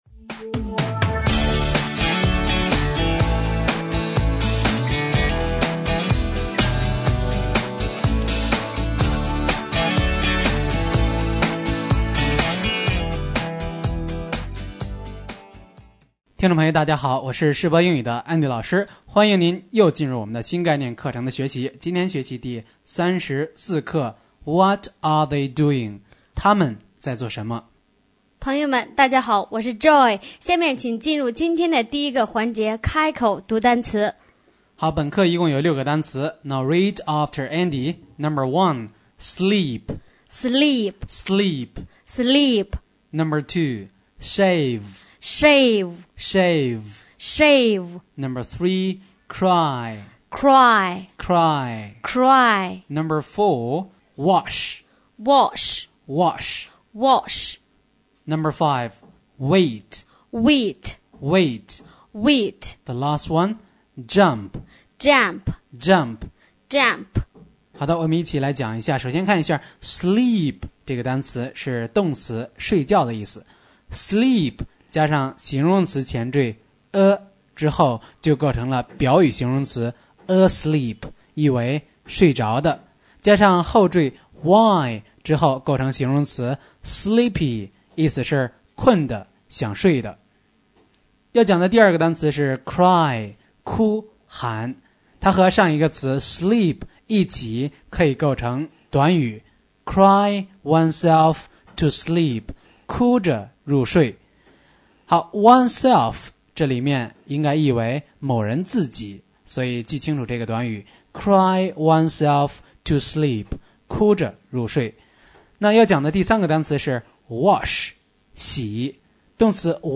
新概念英语第一册第34课【开口读单词】